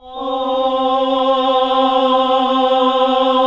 AAH C2 -L.wav